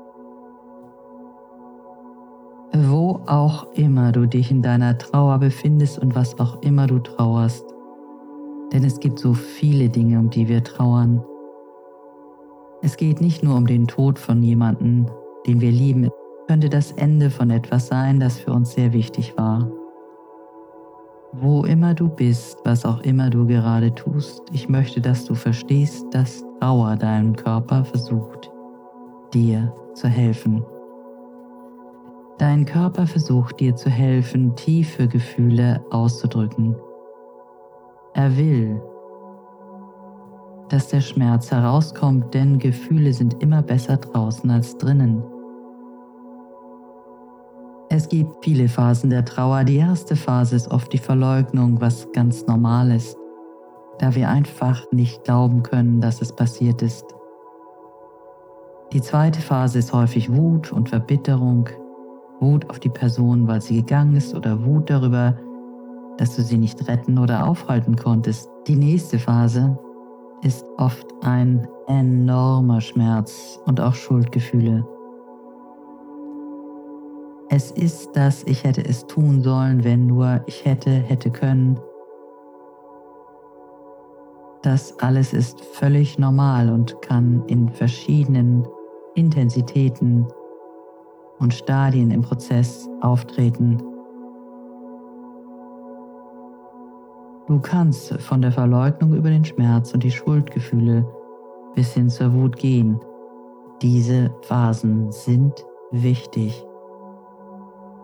• Musik: Ja;